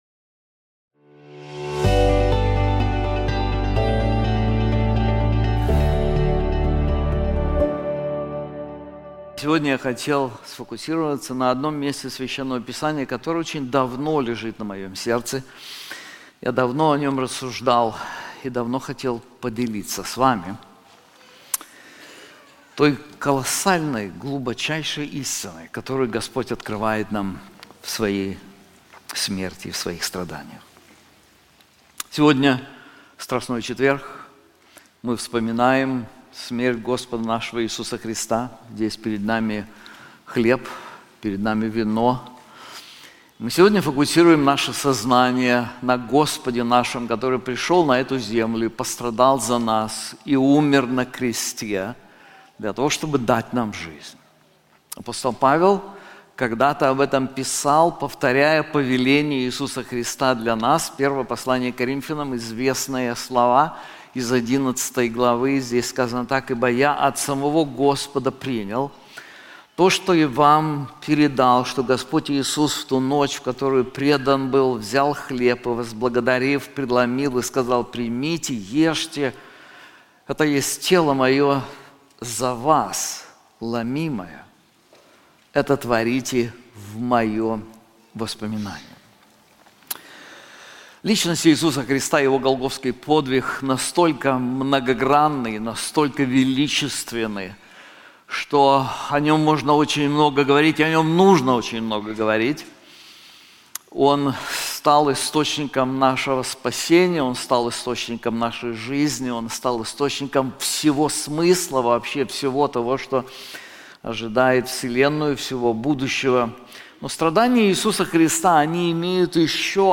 This sermon is also available in English:Christian and the Sufferings of Christ • 1 Peter 2:21